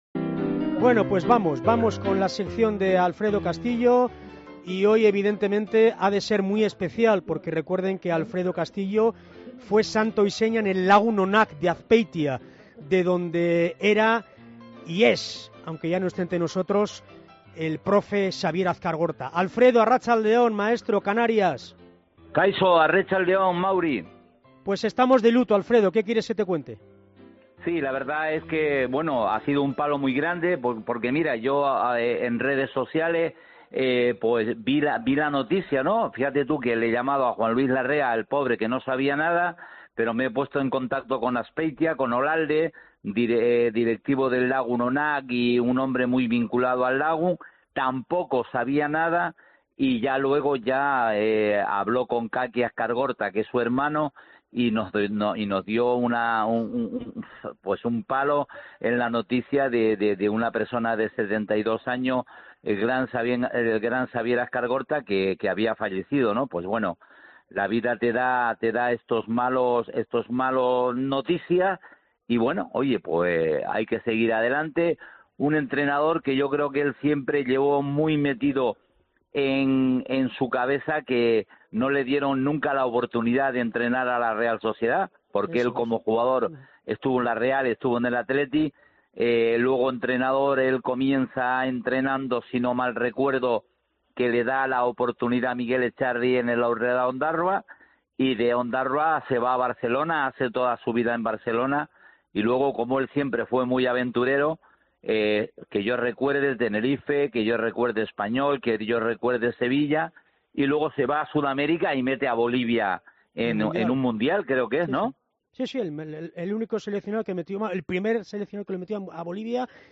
ha analizado la actualidad del equipo 'txuri-urdin' en los micrófonos de Deportes COPE Gipuzkoa